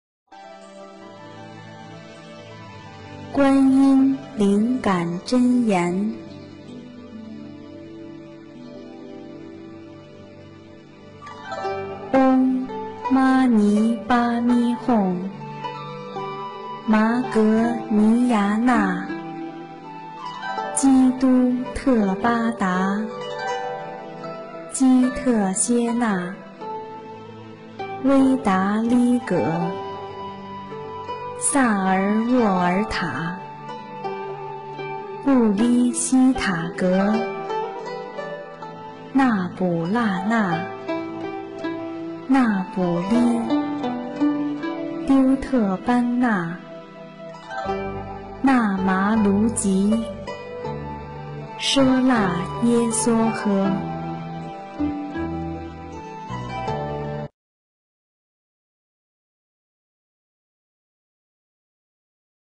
《观音灵感真言》中文·最美大字拼音经文教念